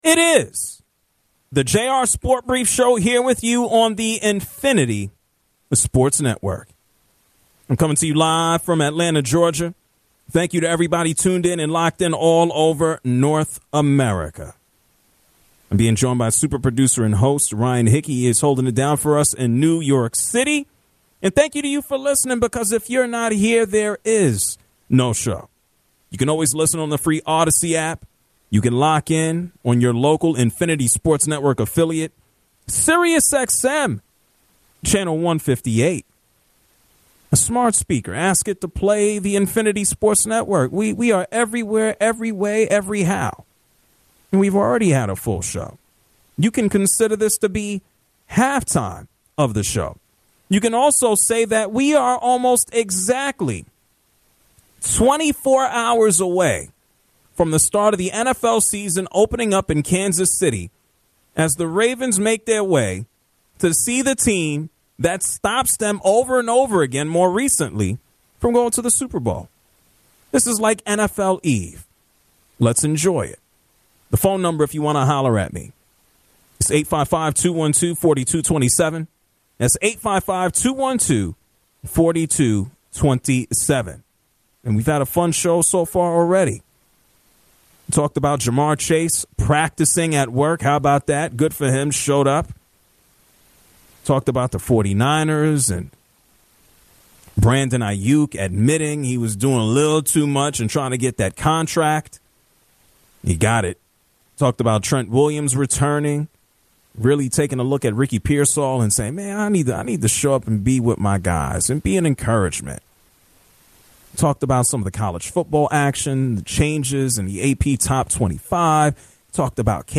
The hour finishes with callers giving their rebuttals on teams that were left off the list.